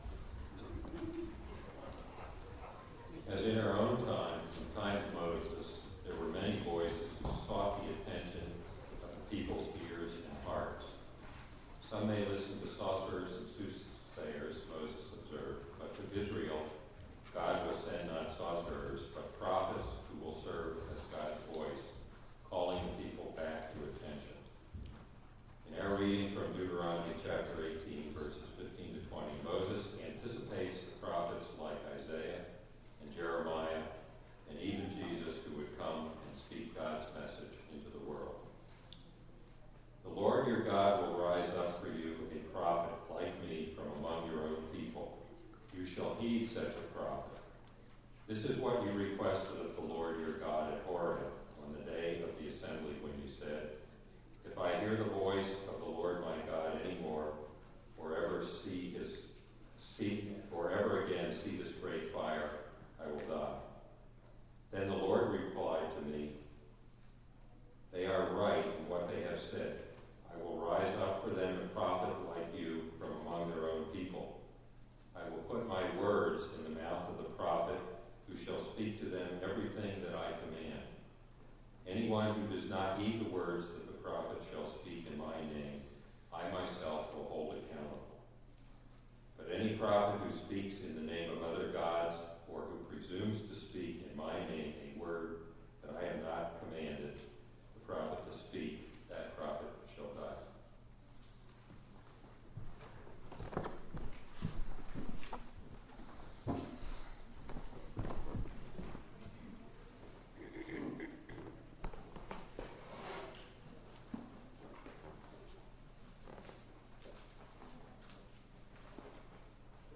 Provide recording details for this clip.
Delivered at: The United Church of Underhill (UCC and UMC)